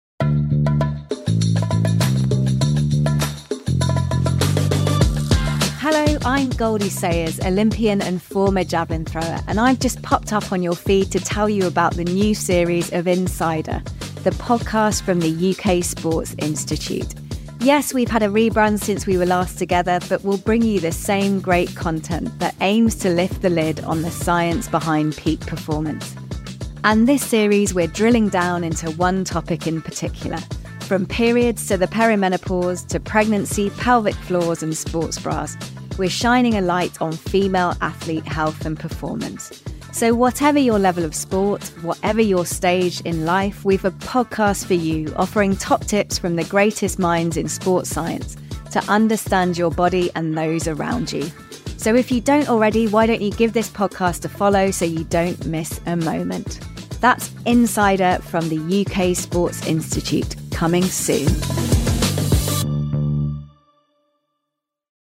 This time, we're covering all aspects of Female Athlete Health and Performance. Here's Olympic bronze medallist Goldie Sayers with more.